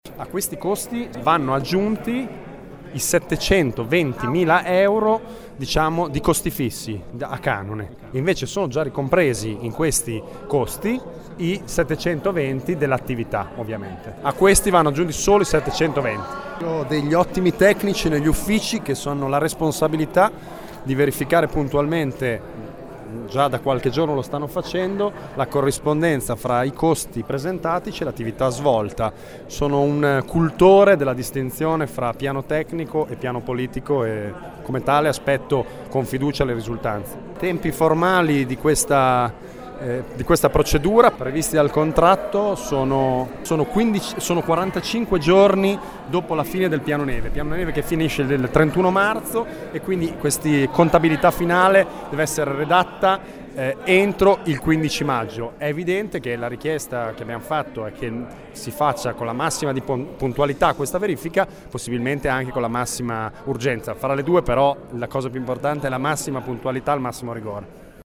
La comunicazione è stata fatta dall’assessore Luca Rizzo Nervo in commissione.
Ascolta Rizzo Nervo